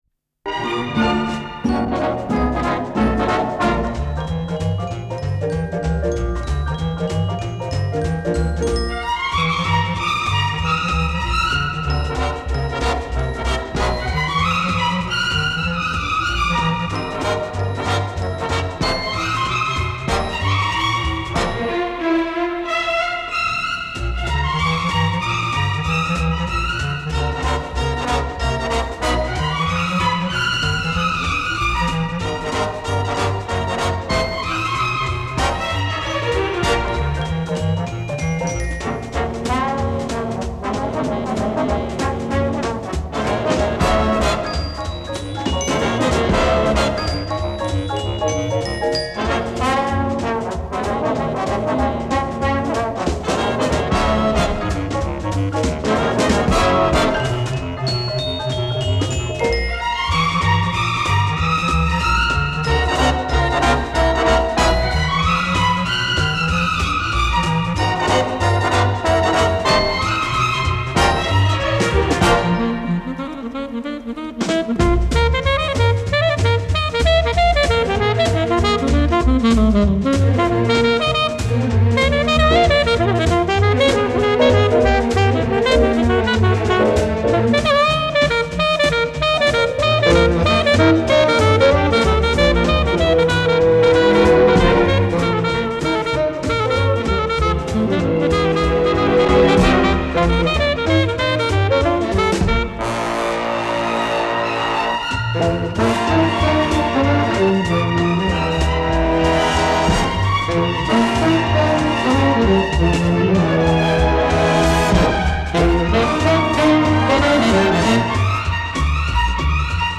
Пьеса для скрипки и эстрадного оркестра 2. Самба для скрипки и эстрадного оркестра 3. Народная мелодия